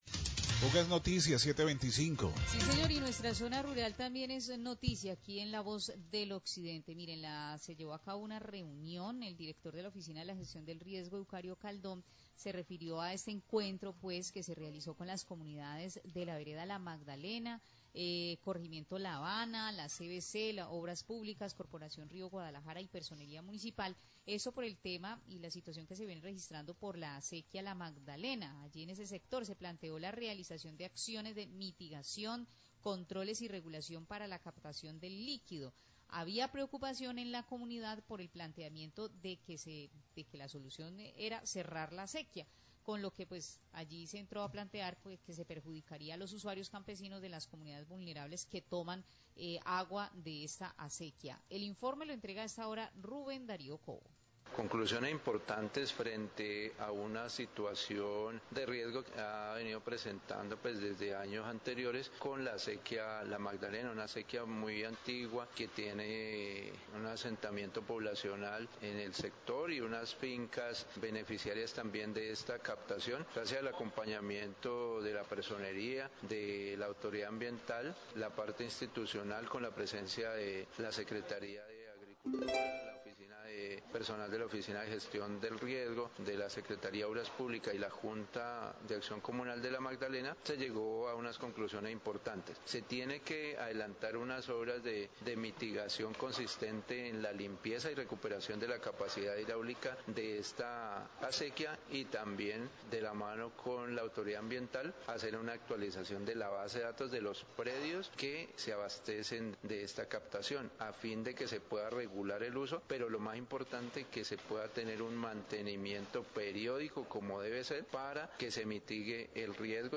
Radio
Secretario de Gestión de Riesgo de Buga habló sobre el encuentro que se realizó con comunidades del corregimiento La Habana, vereda La Magdalena, la CVC, obras públicas, para las acciones de mitigación del riesgo y control de la acequia la Magdalena.